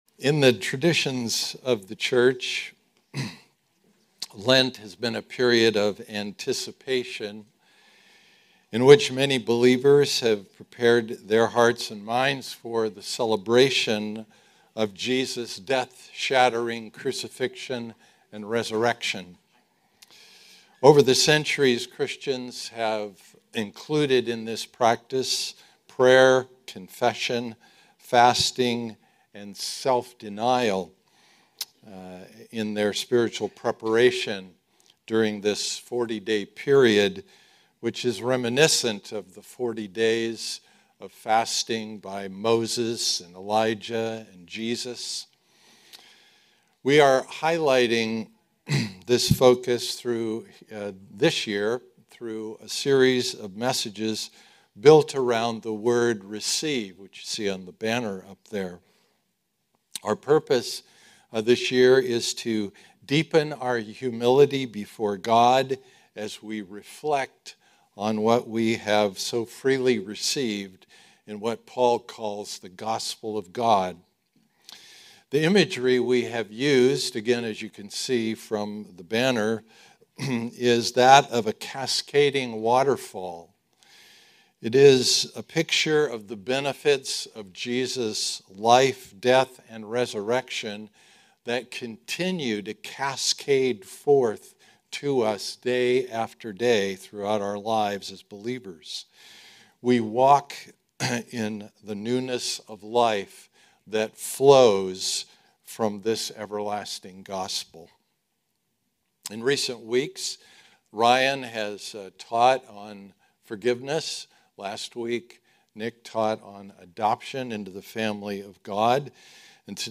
Sermons Book